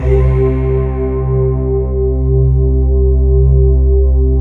PAD K-20006L.wav